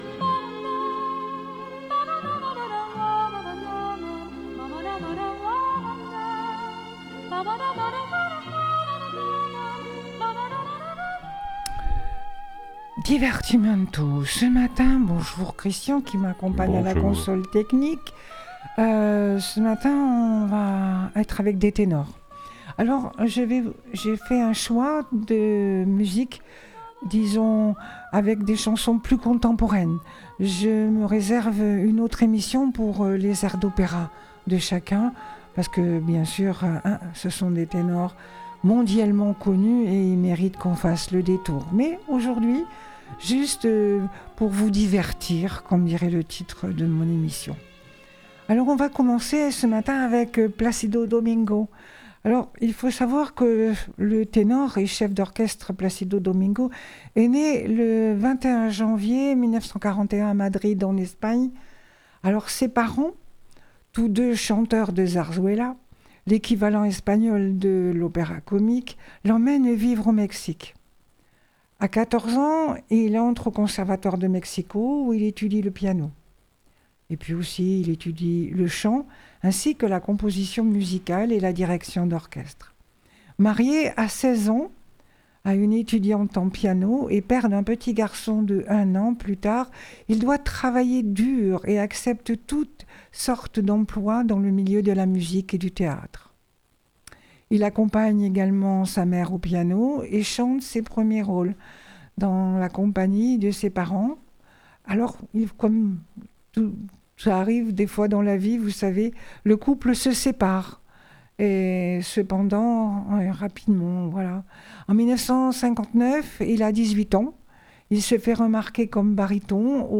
Les ténors lyriques chantent le répertoire de la variété 17.02.26